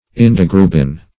Indigrubin \In`dig*ru"bin\, n. [Indigo + L. ruber red.]